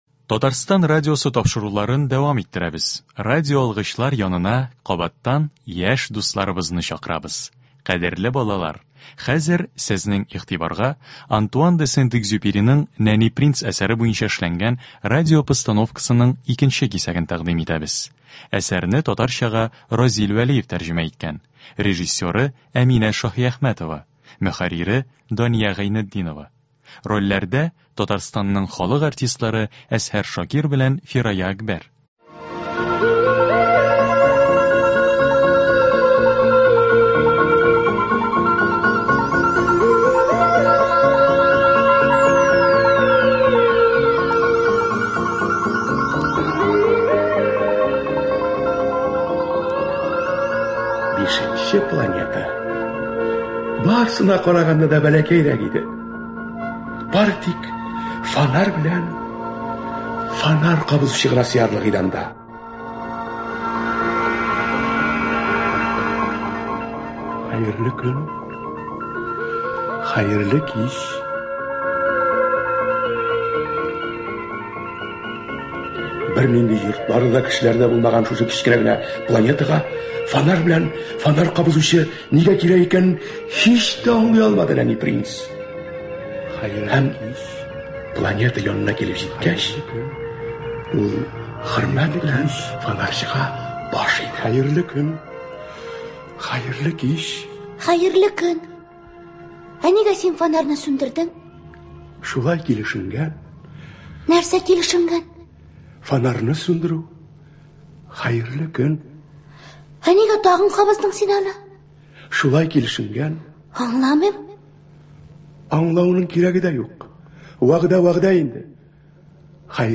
Радиотамаша.
Кадерле балалар, бүген сезнең игътибарга Антуан де Сент-Экзюпериның “Нәни принц” әсәре буенча эшләнгән радиопостановка тәкъдим итәбез.